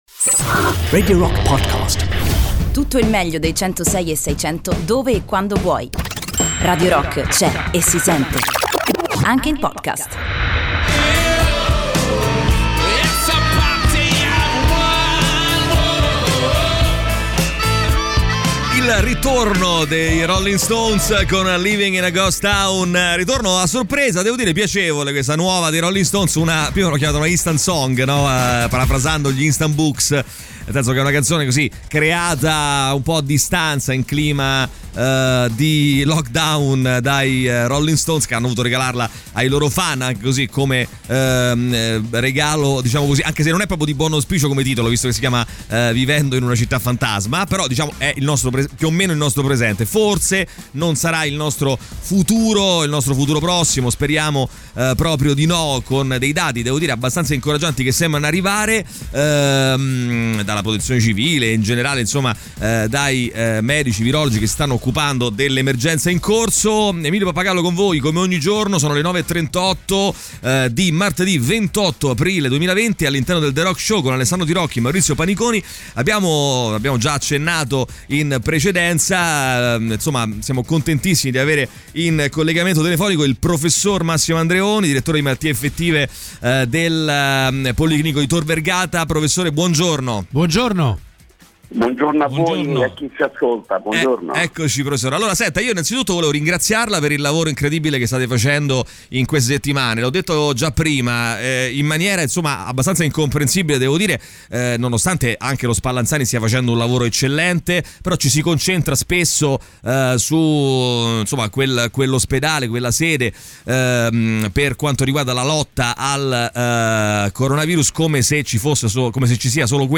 Intervista
Collegamento Telefonico